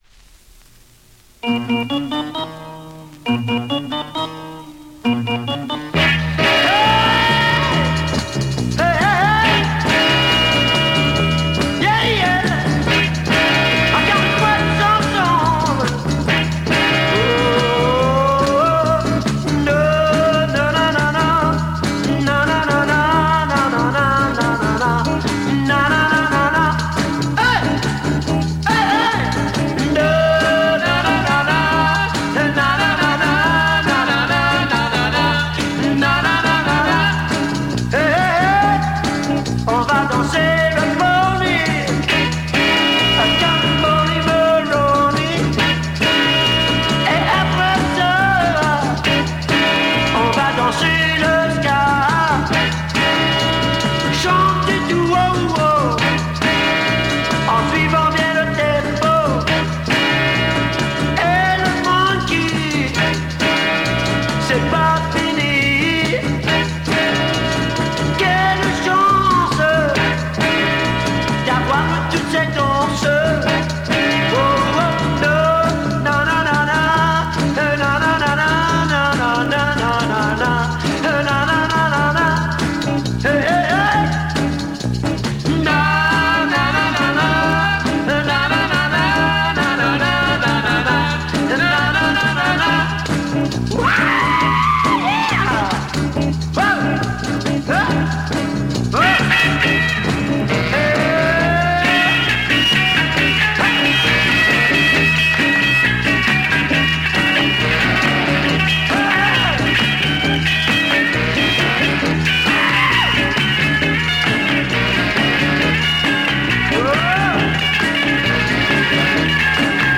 French Quebec Row Beat mod dancer
Top Freakbeat mod sound